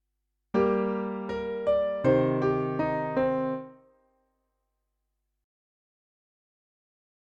Using v leading to i, both minor chords, presented either as harmony or implied by the melodic movement:
Example 8 Cadence